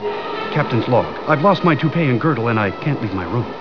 Below is my collection of sounds from the TV show MST3K and MST3K the movie.
Crow imitates William Shatner